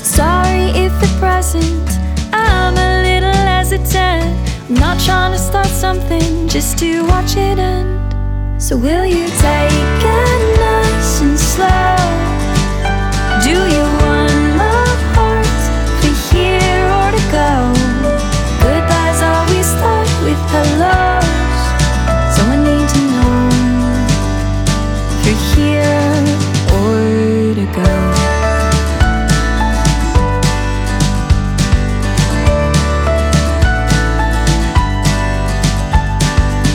Acoustic Light Rock / Pop